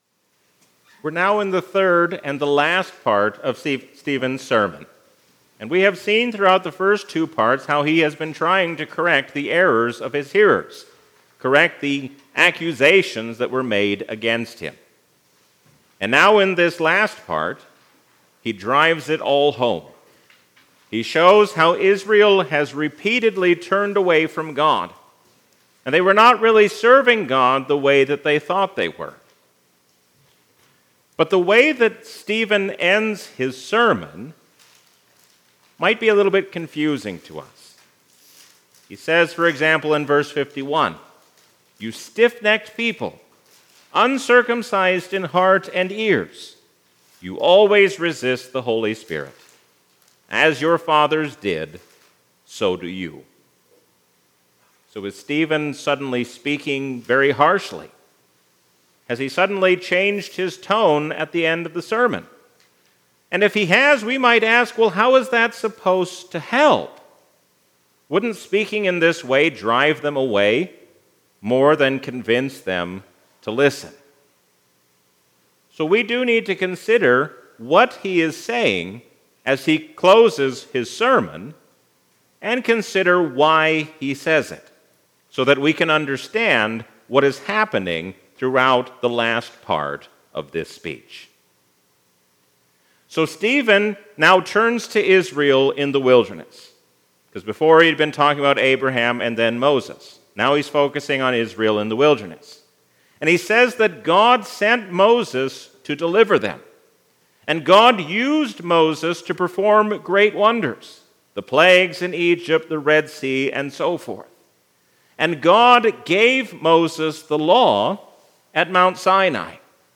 A sermon from the season "Epiphany 2025." Stephen shows us what it means to be like Jesus even in a difficult hour.